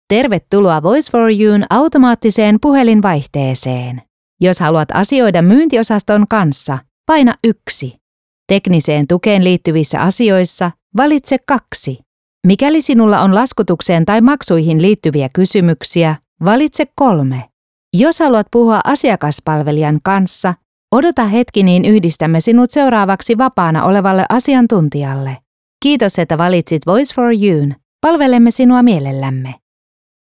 IVR
I record in a professional studio environment with professional recording equipment.
ConversationalTrustworthyEnergeticNeutralExpressive